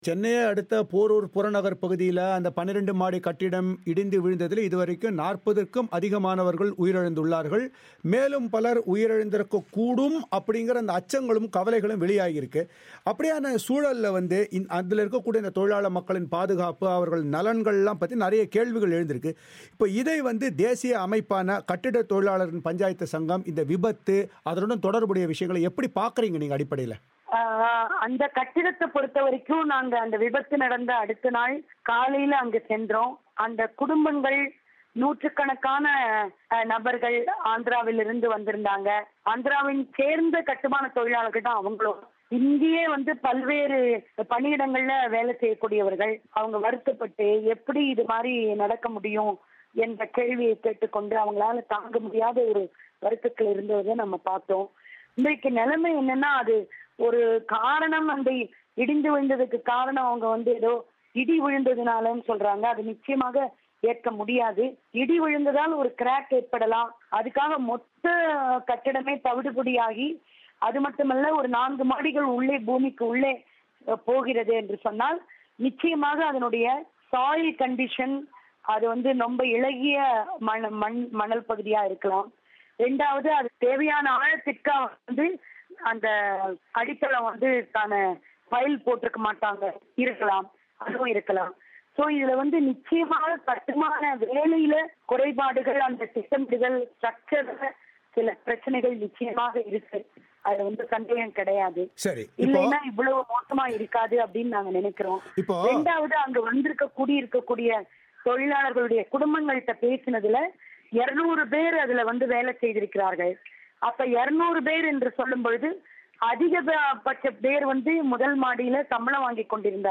அவர் தமிழோசைக்கு வழங்கிய பிரத்தியேகப் பேட்டியை இங்கே கேட்கலாம்.